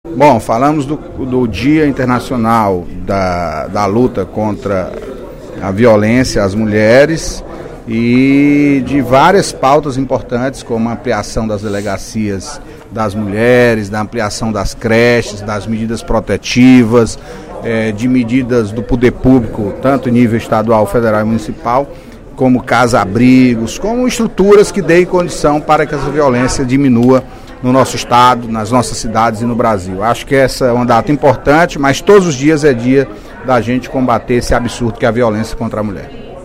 O deputado Antonio Carlos (PT) destacou, no primeiro expediente da sessão plenária da Assembleia Legislativa desta sexta-feira (22/11), o Dia Internacional da Não-Violência Contra a Mulher, celebrado na segunda-feira, dia 25.